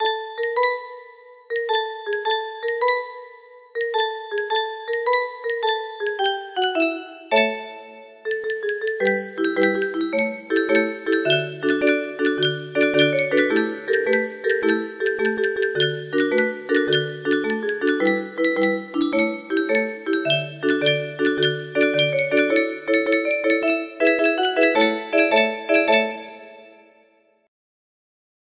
Washington Post March music box melody
From a modified 20 note Celestina organette midi